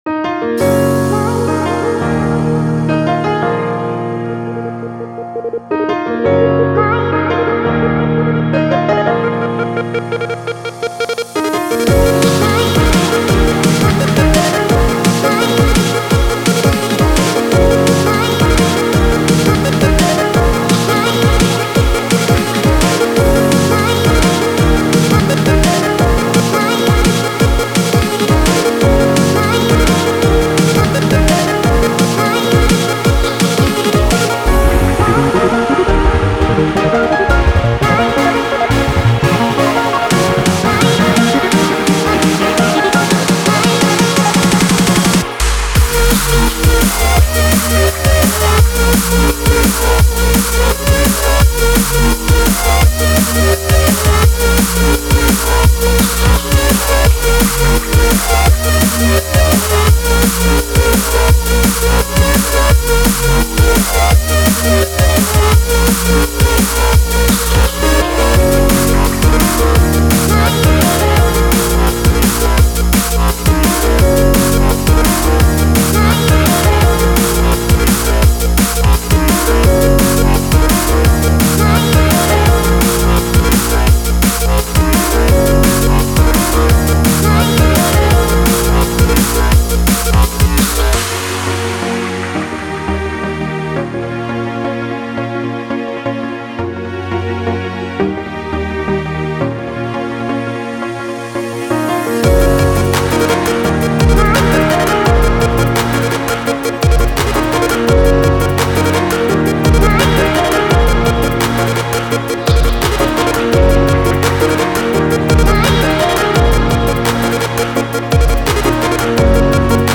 Drum & Bass